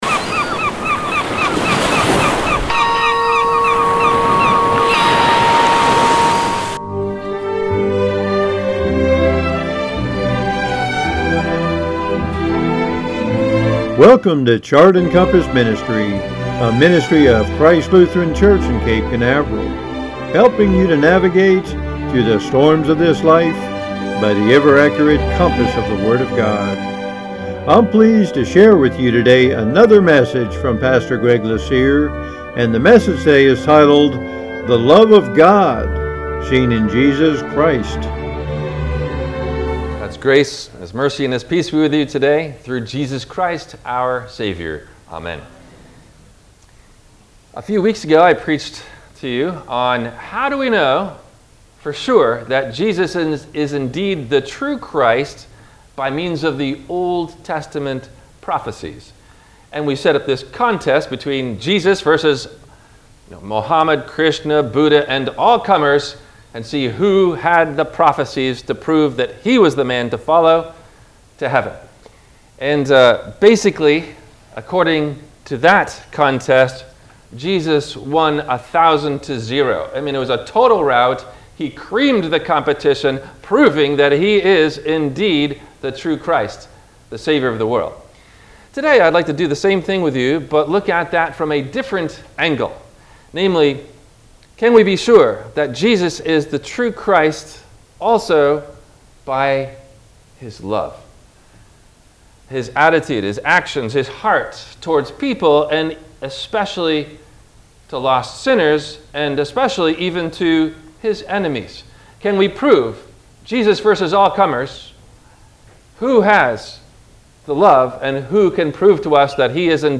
Did Jesus Come The 1st Time For Peace or War? – WMIE Radio Sermon – December 20 2021